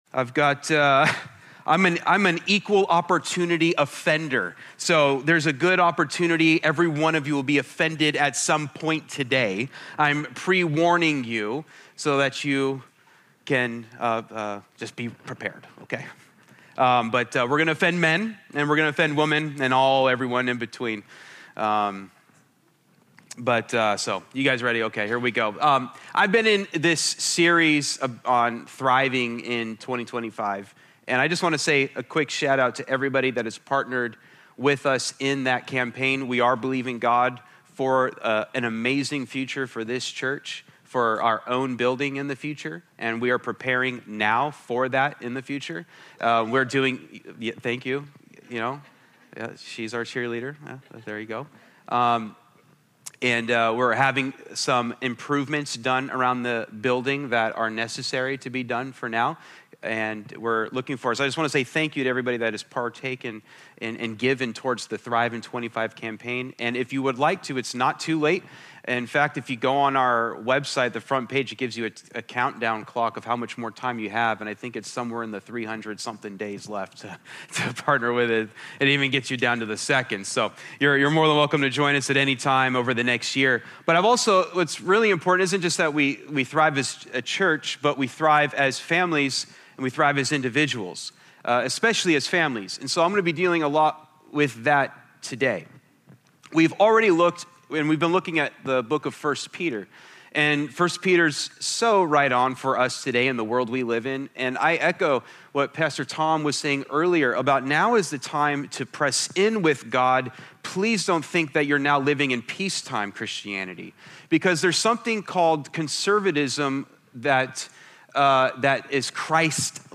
Sermons | Church of Grace